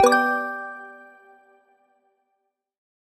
feedback-correct.mpeg